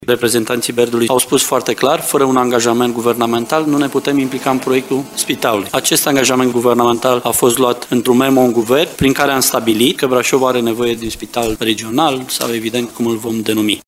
În cazul noului spital, BERD are nevoie de implicare guvernamentală, lucru care s-a și întâmplat, a explicat senatorul de Brașov, Marius Dunca, ministrul Tineretului şi Sportului: